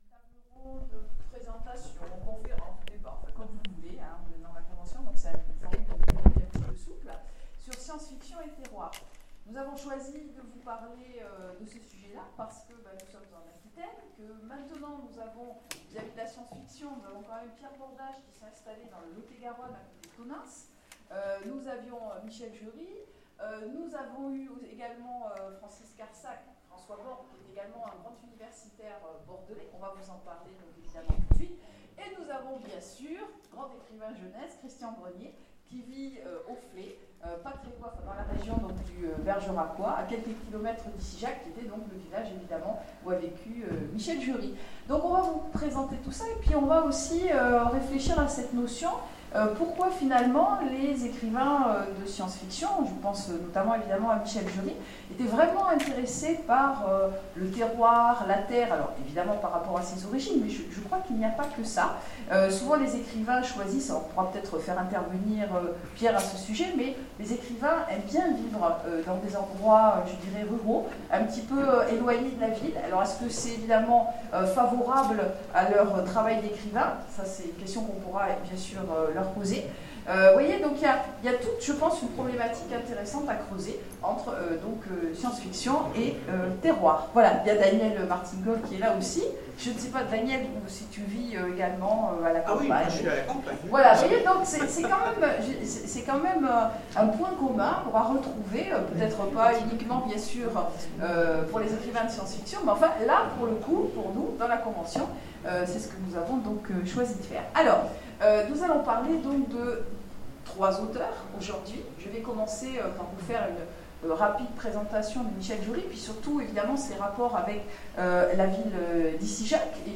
Convention SF 2016 : Conférence SF et terroir